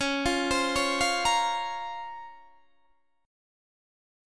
DbM13 Chord
Listen to DbM13 strummed